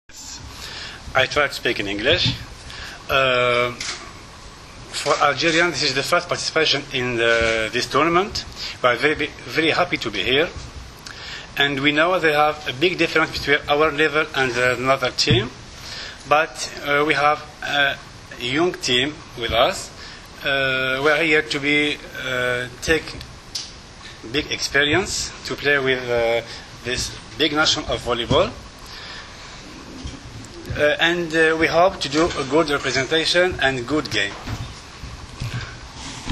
U beogradskom hotelu “M” danas je održana konferencija za novinare povodom predstojećeg turnira F grupe II vikenda XXI Gran Prija 2013.
IZJAVA